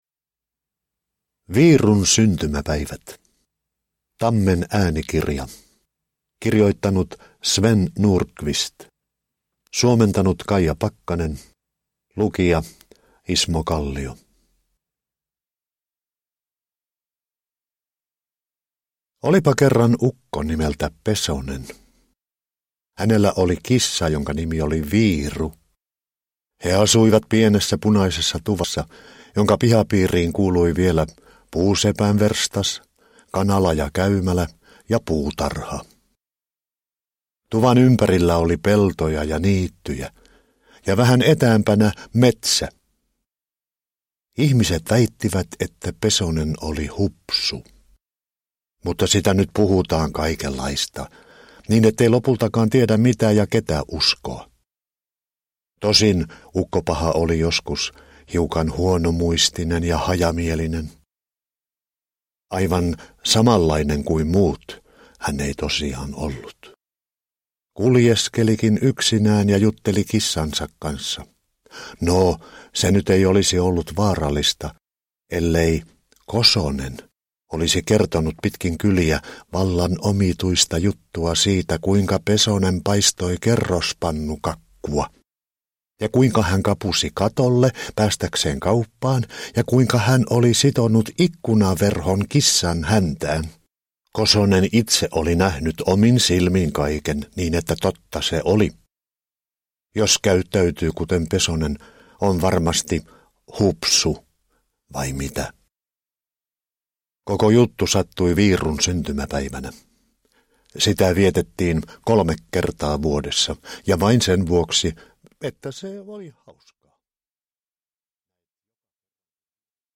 Viirun syntymäpäivät – Ljudbok – Laddas ner
Uppläsare: Ismo Kallio